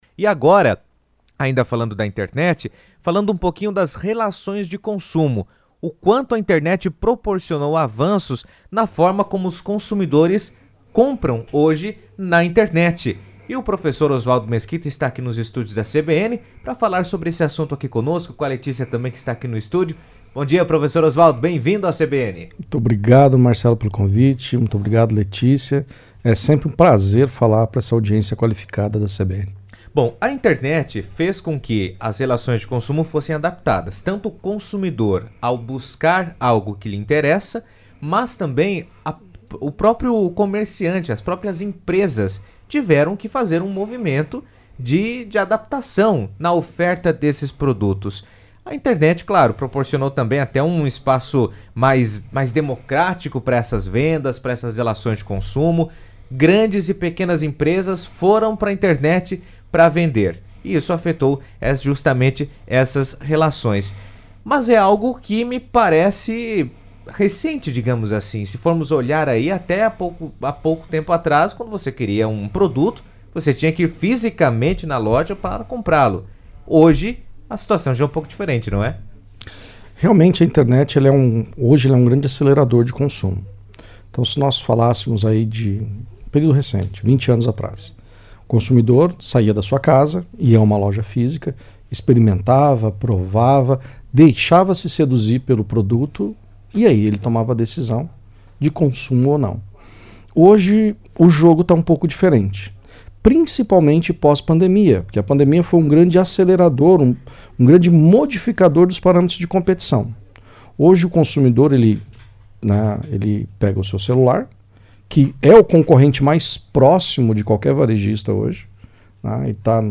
A presença nas redes sociais, o uso de marketplaces e o atendimento personalizado online são algumas das ações adotadas para ampliar as vendas e fidelizar o público. Em entrevista à CBN Cascavel